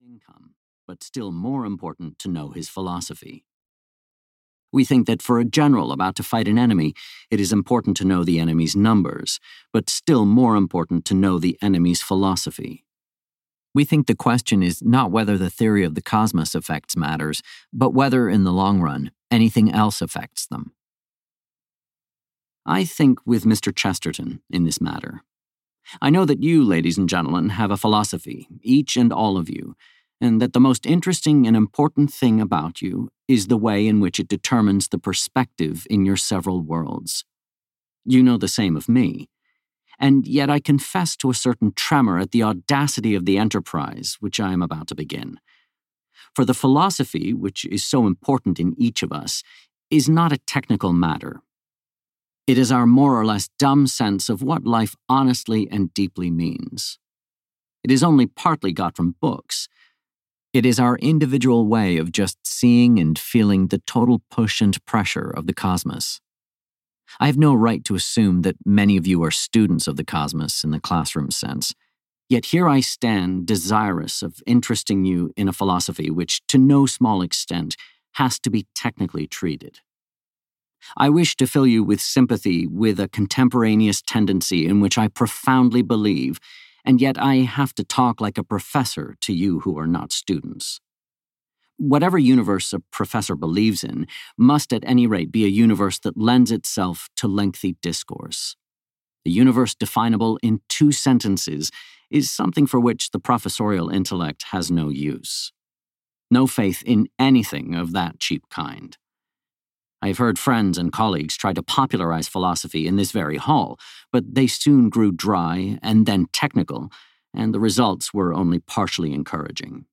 Pragmatism and The Meaning of Truth (EN) audiokniha
Ukázka z knihy
pragmatism-and-the-meaning-of-truth-en-audiokniha